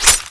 y23s1sfsmg_clipout.wav